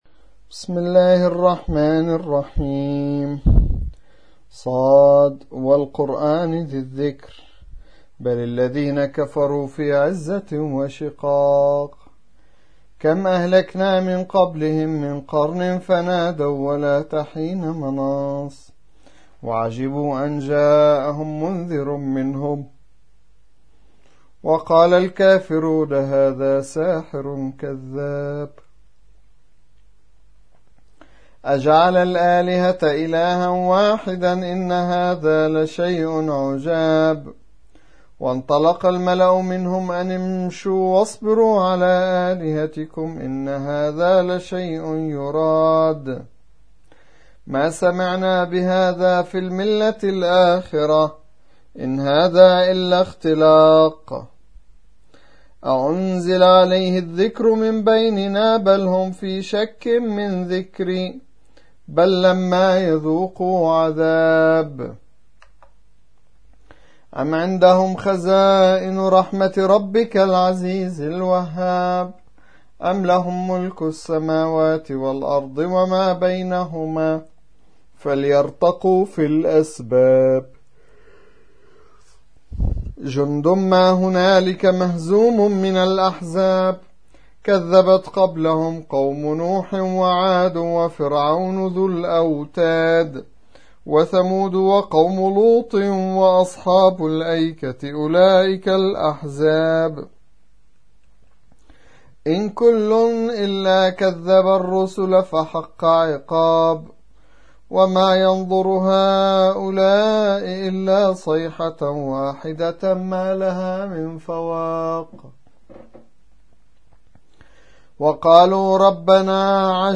38. سورة ص / القارئ